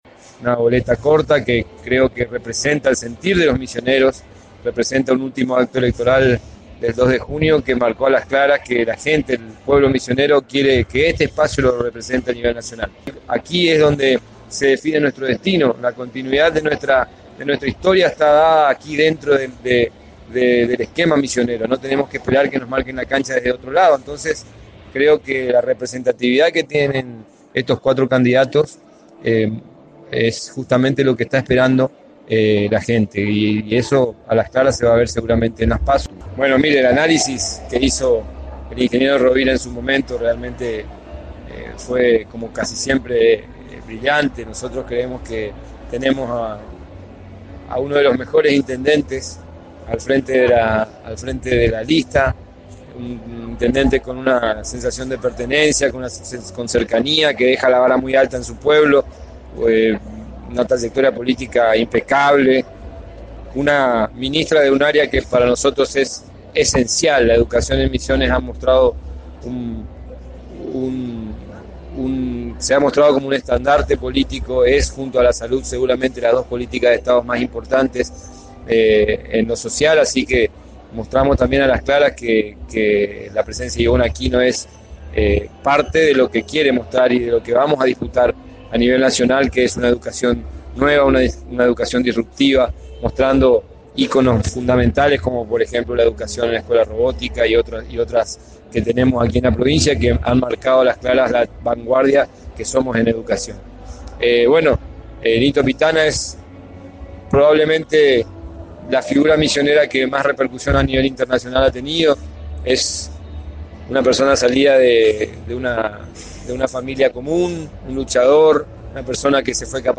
En charla exclusiva el ministro de salud Walter Villalba destacó las cualidades, uno a uno, de los candidatos misioneristas para las próximas Primarias Abiertas Simultáneas Obligatorias (P.A.S.O.) y resaltó que la boleta corta es una experiencia nueva que representa lo que quiere el misionero y lo demostró en las últimas elecciones con su voto.